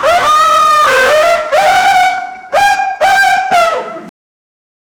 Free AI Sound Effect Generator
an-embarassed-racoon-bp4cg4wd.wav